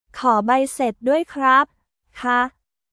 コー バイセッ ドゥアイ クラップ／カー